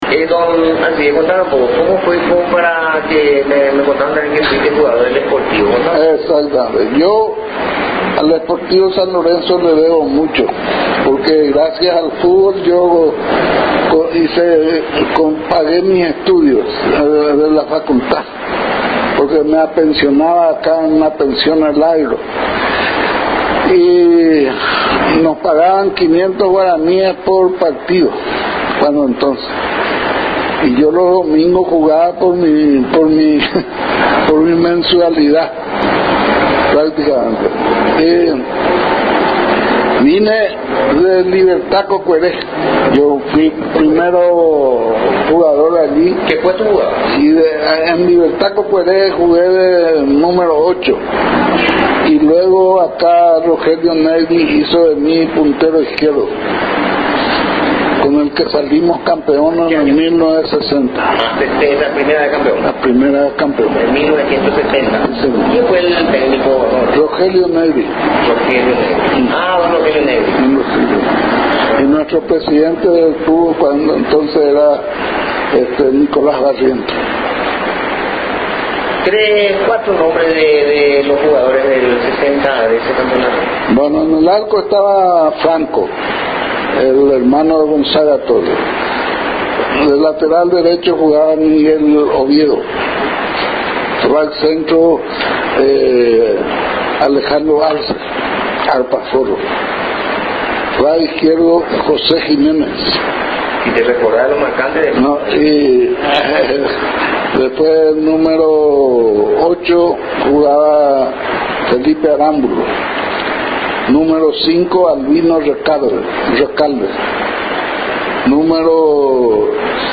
Aquí parte de la extensa entrevista